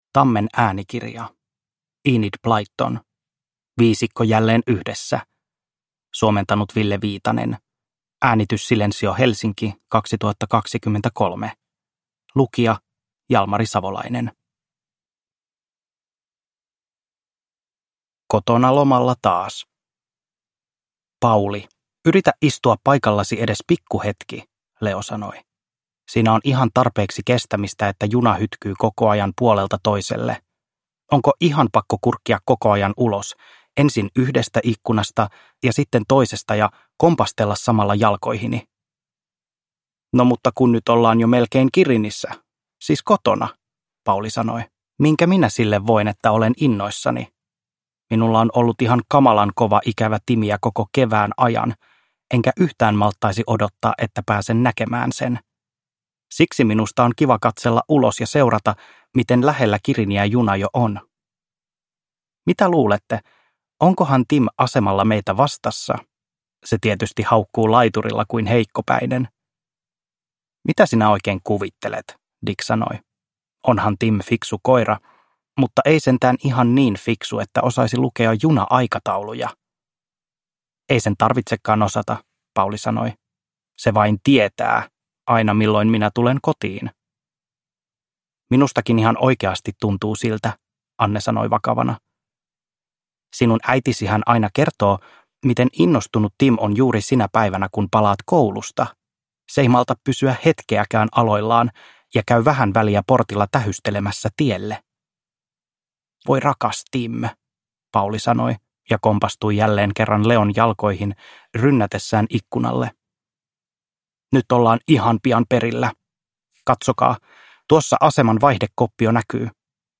Viisikko jälleen yhdessä – Ljudbok – Laddas ner